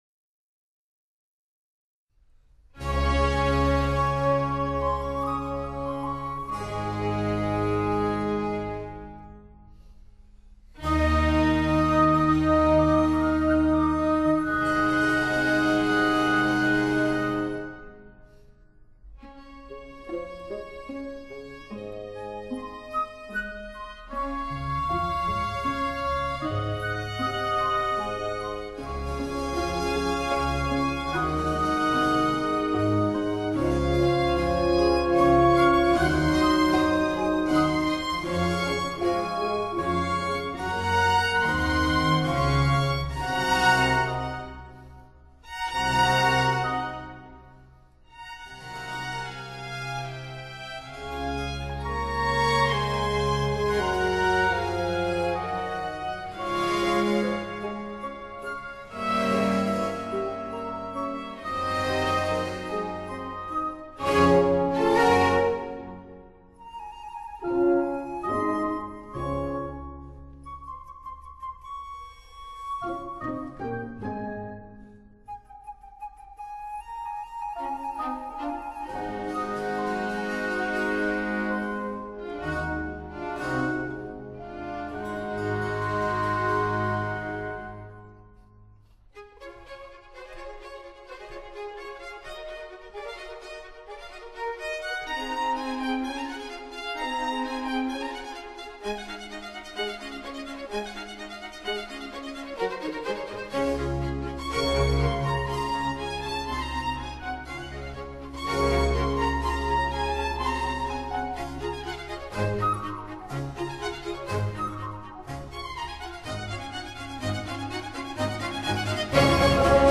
Obertura